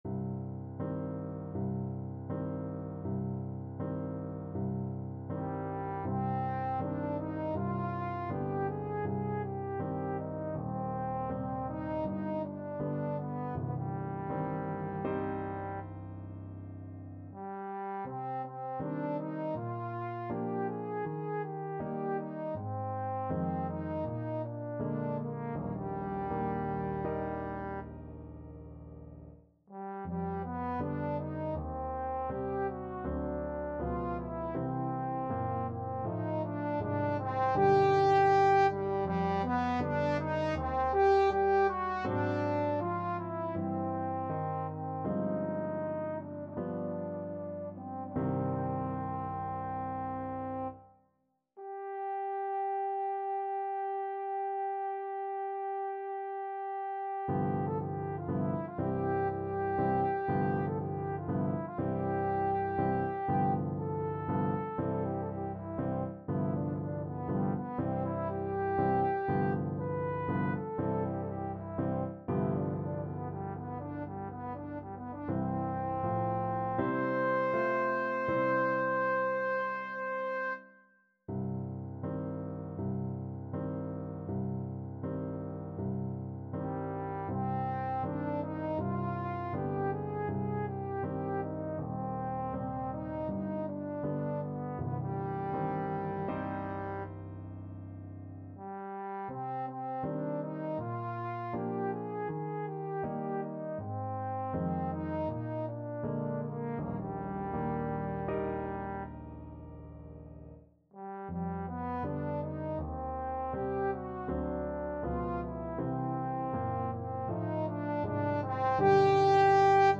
Trombone version
TrombonePiano
4/4 (View more 4/4 Music)
Un poco andante
Classical (View more Classical Trombone Music)